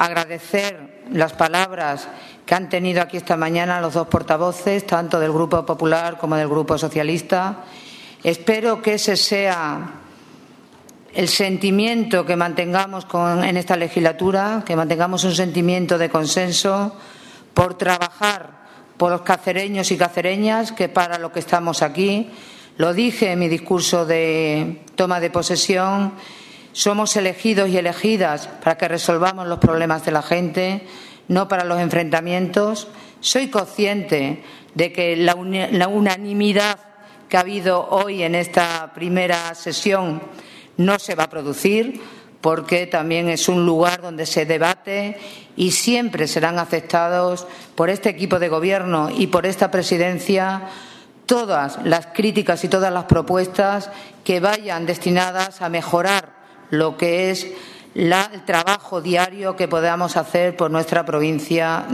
CORTES DE VOZ
Audio_Ch._Cordero_Pleno.mp3